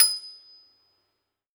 53s-pno26-D6.aif